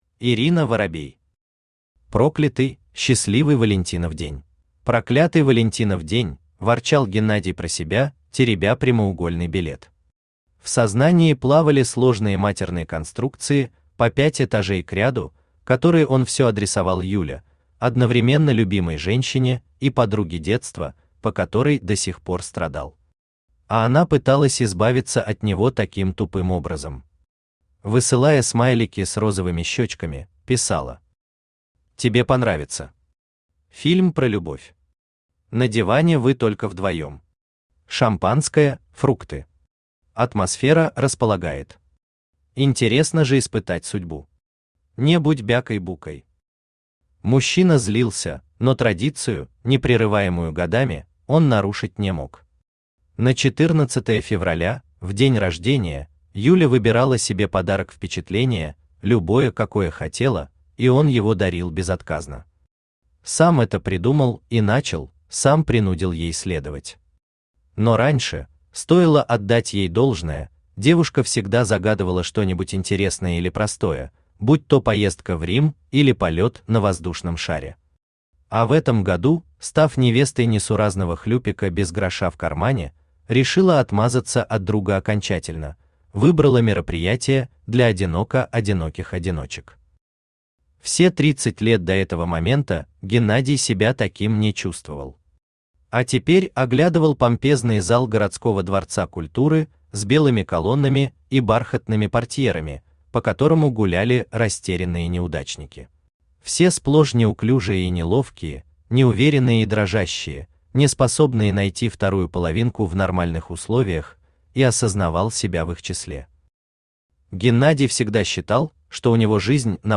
Аудиокнига Проклятый/Счастливый Валентинов день | Библиотека аудиокниг
Aудиокнига Проклятый/Счастливый Валентинов день Автор Ирина Воробей Читает аудиокнигу Авточтец ЛитРес.